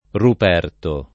Ruperto [ rup $ rto ]